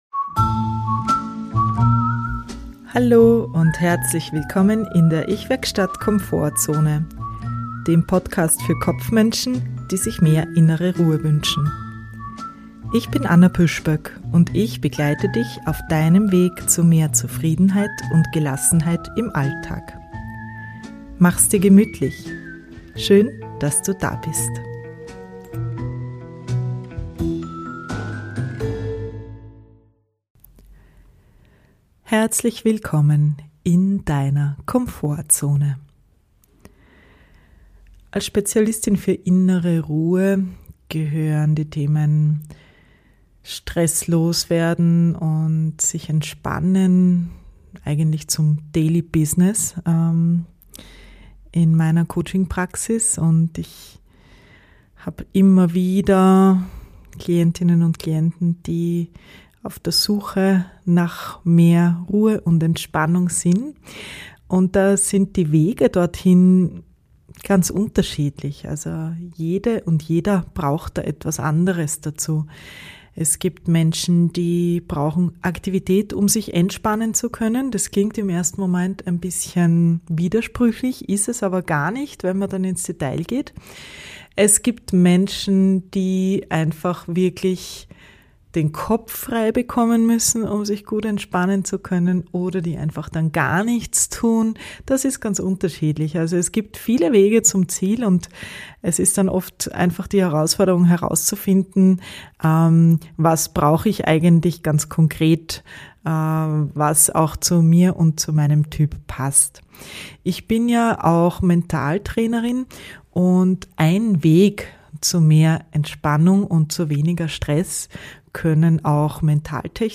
Eine angeleitete Imaginationsübung zum Entspannen